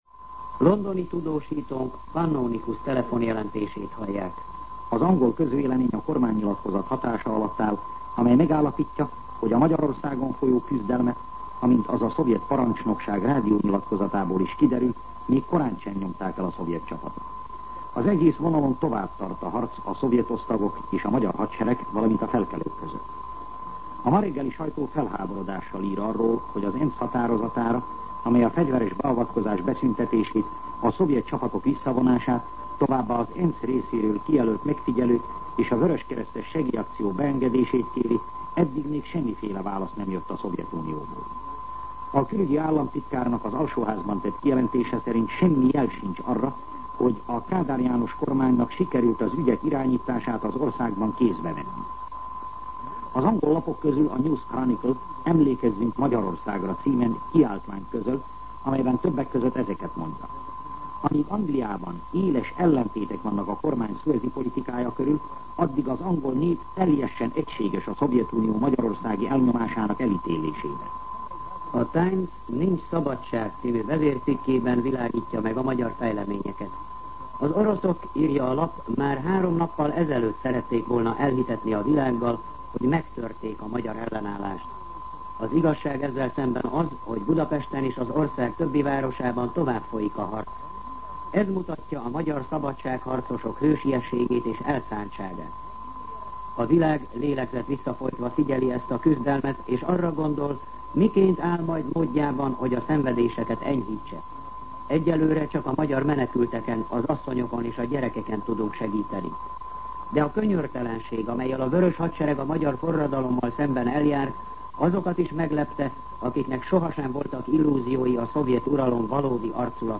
MűsorkategóriaTudósítás